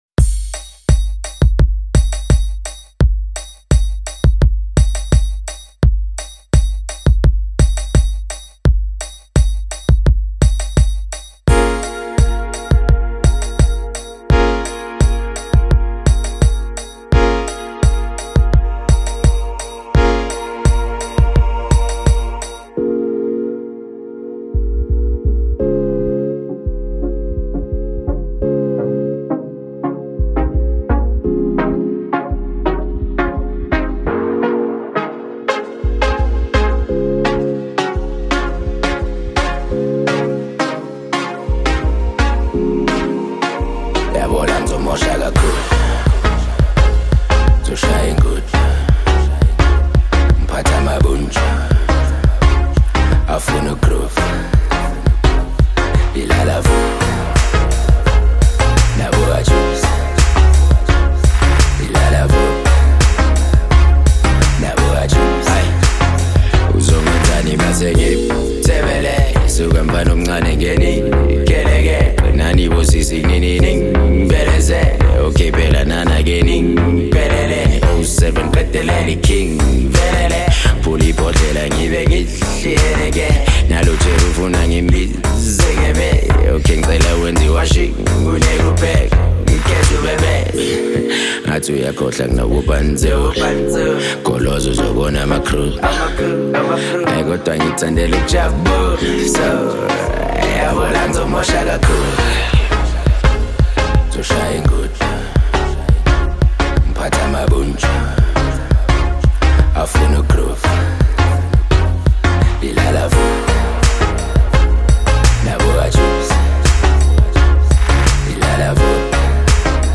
inspired by old school Kwaito